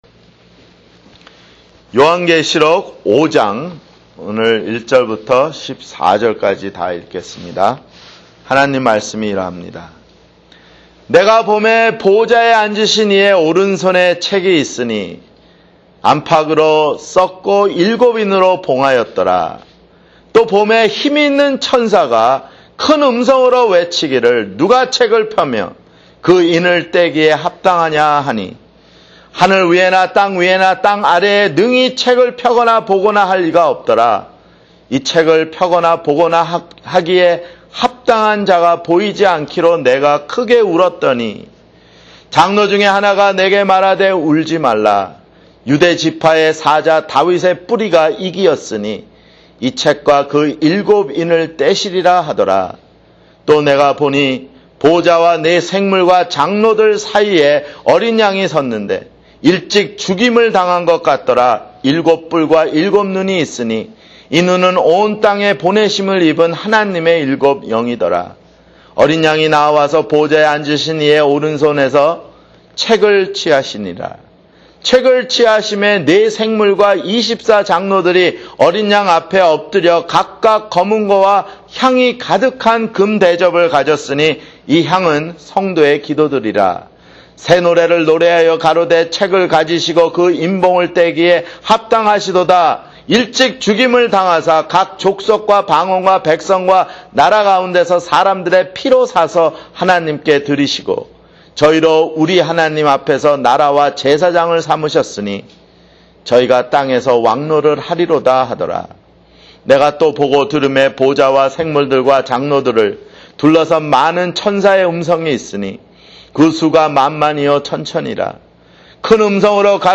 [주일설교] 요한계시록 (23)